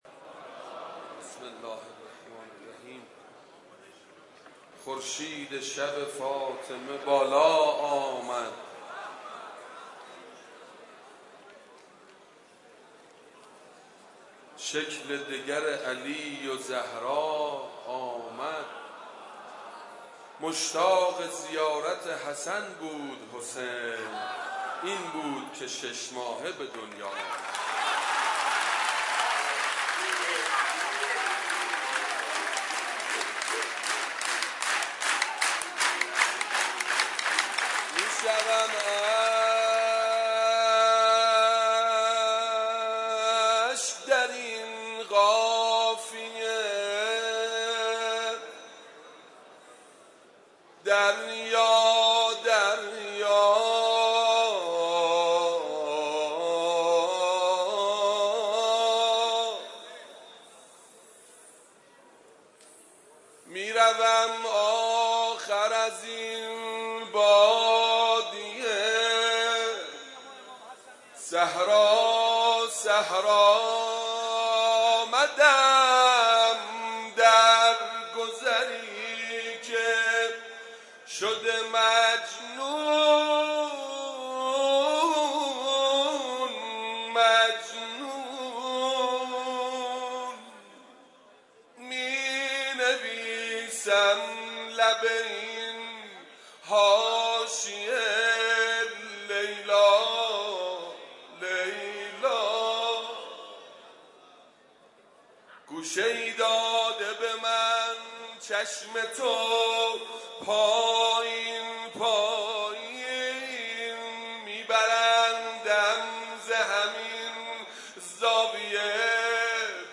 شب میلاد امام حسن مجتبی(ع) رمضان93